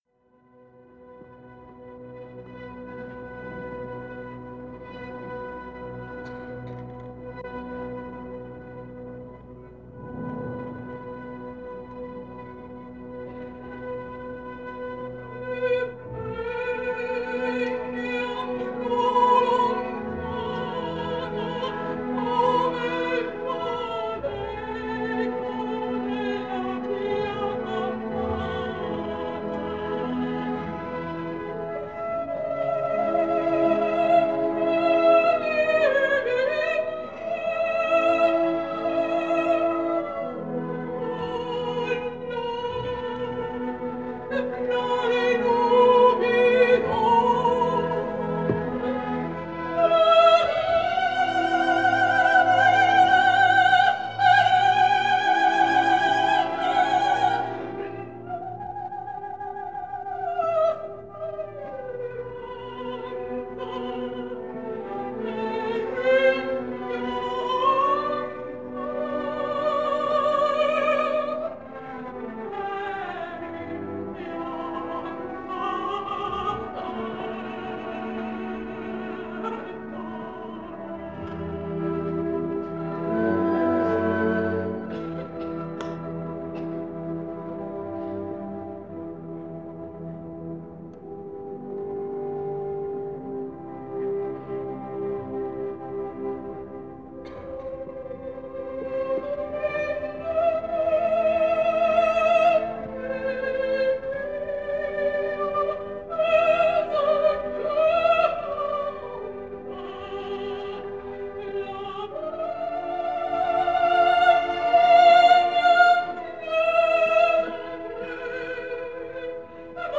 Жанр: Opera
итальянская оперная певица, сопрано.